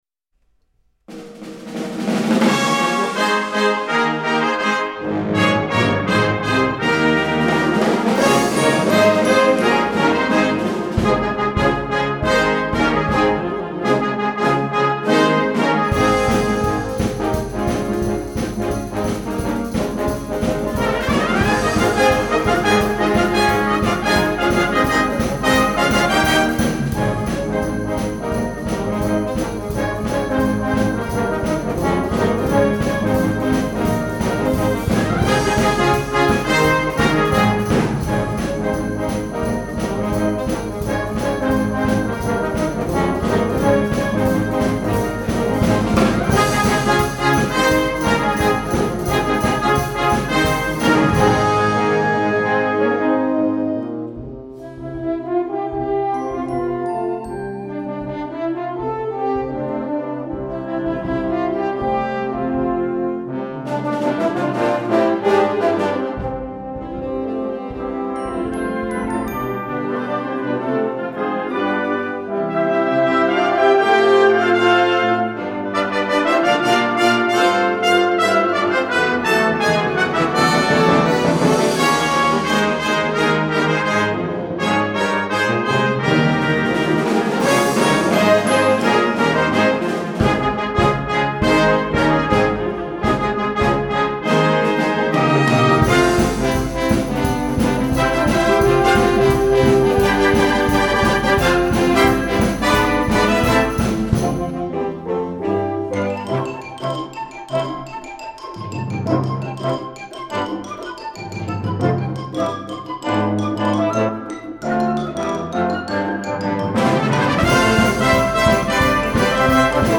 Gattung: Konzertwerk
A4 Besetzung: Blasorchester Tonprobe